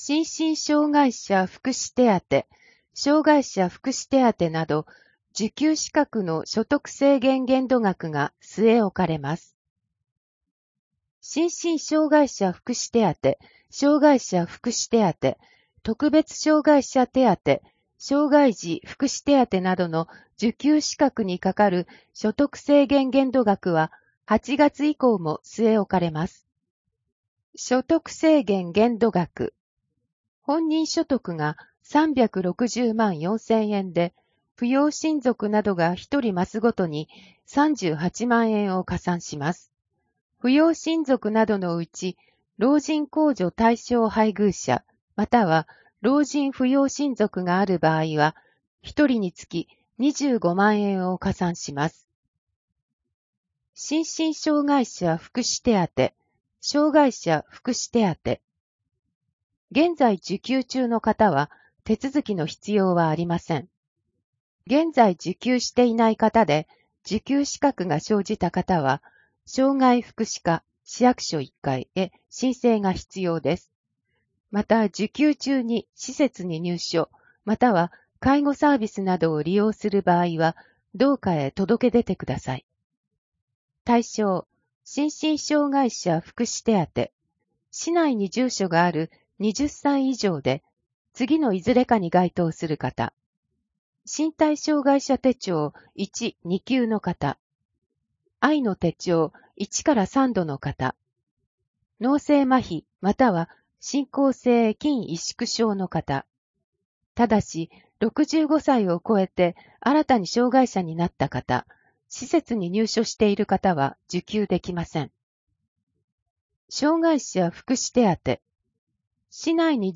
声の広報（平成27年8月1日号）